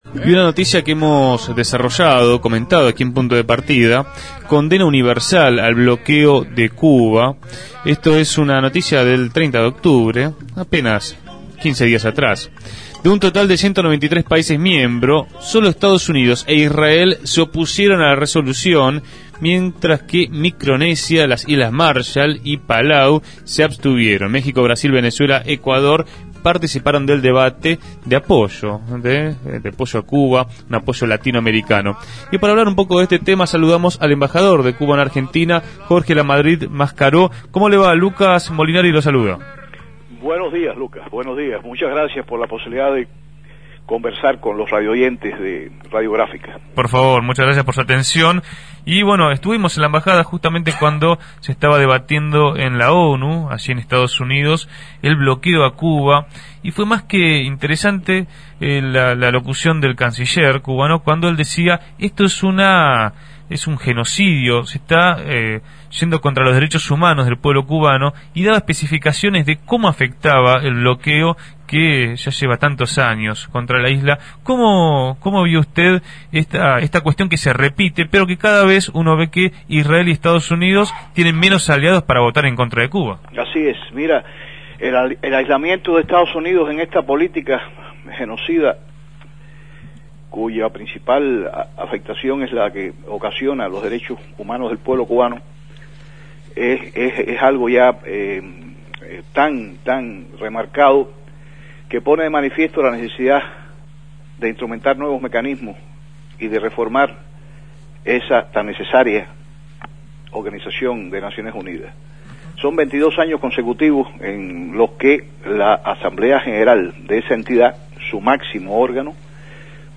Jorge Lamadrid Mascaró, embajador de Cuba en la Argentina, fue entrevistado en Punto de Partida. Reflexionó sobre la contundente votación, las políticas de Estados Unidos y el apoyo internacional al país caribeño.